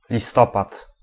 Ääntäminen
IPA: /nʊˈvɛmbɛr/